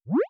SFX_Dialog_Open_04.wav